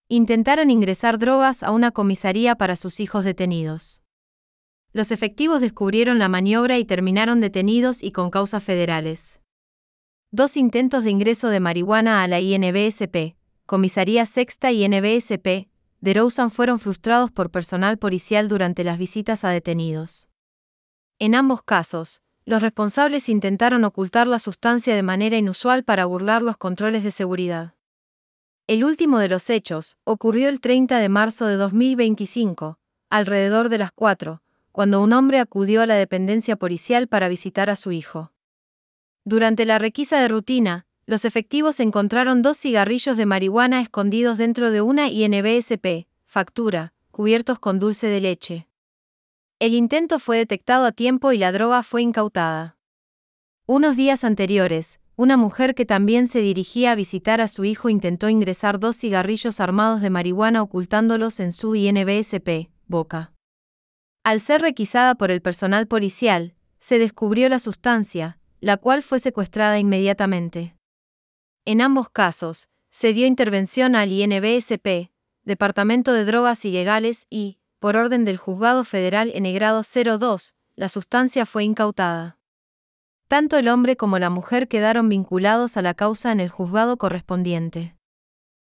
Text_to_Speech-11.wav